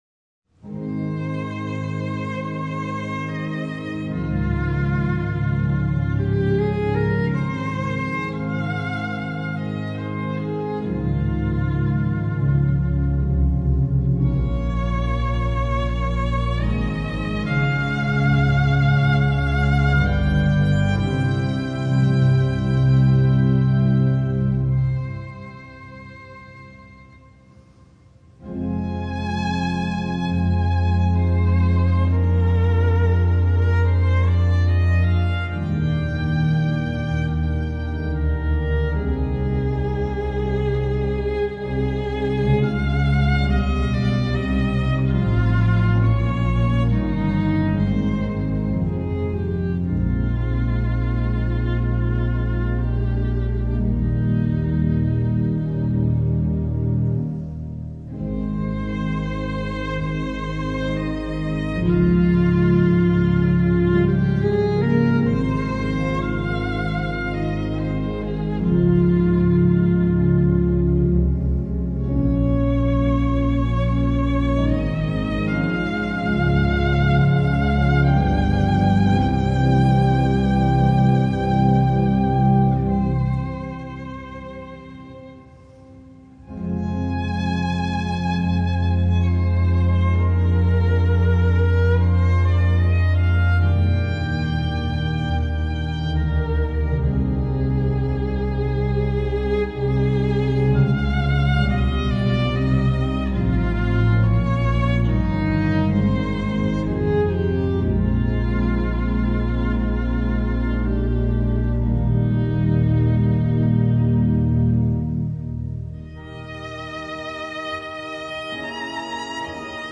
Voicing: Instrument and Organ